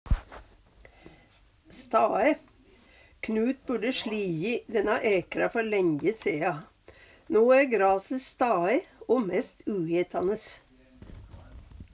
stae - Numedalsmål (en-US)